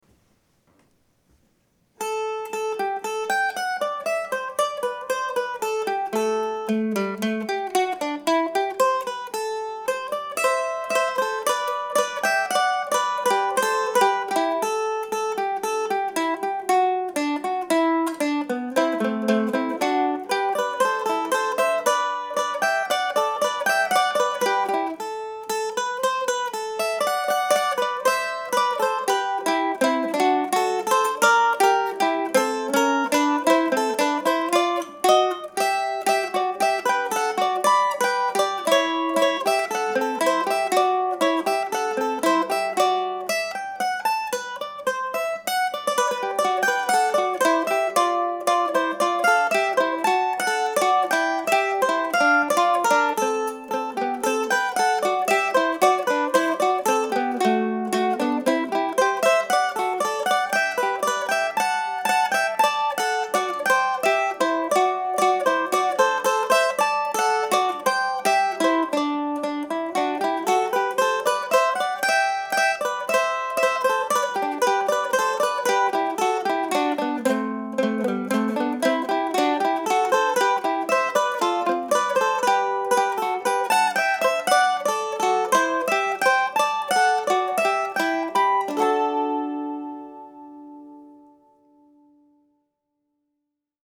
So here is no. 5, recorded on Sunday using a 1920 or 1921 Gibson A-2 mandolin.